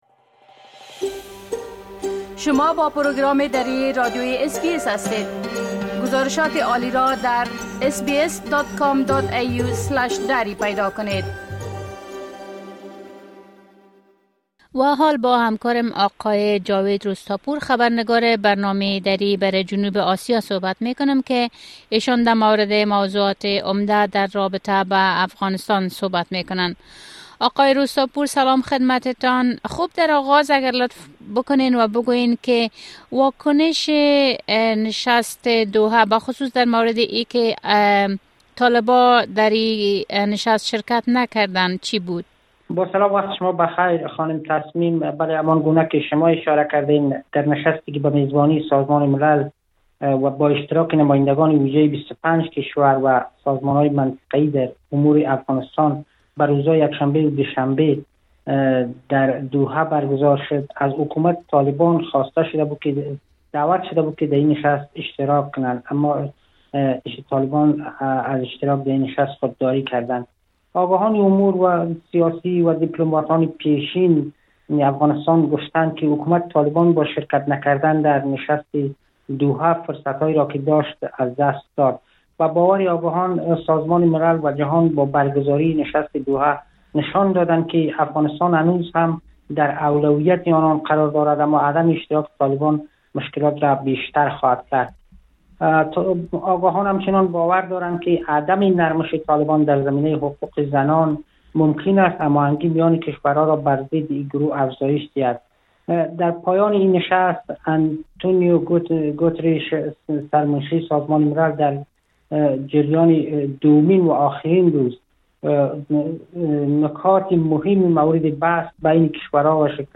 گزارش كامل خبرنگار محلی ما، به شمول اوضاع امنيتى و تحولات مهم ديگر در افغانستان، را این‌جا بشنوید.